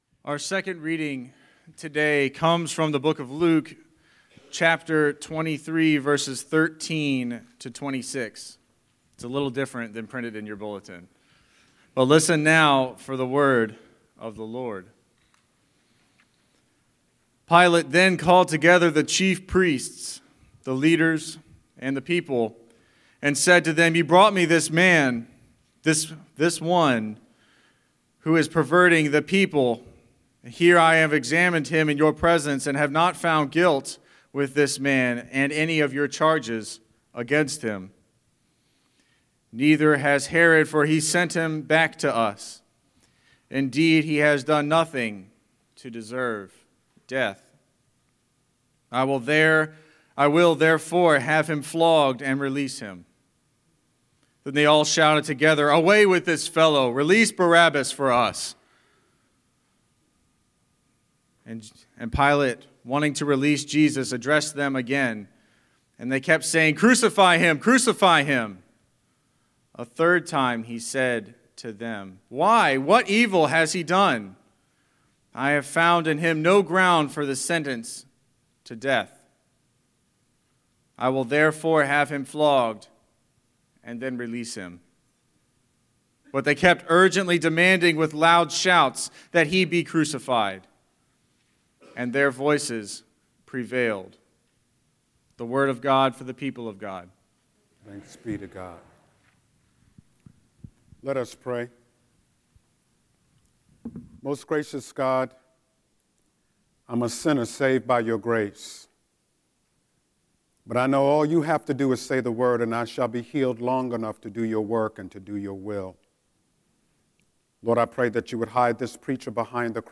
Listen to this week’s Scripture and Sermon
03-20-Scripture-and-Sermon.mp3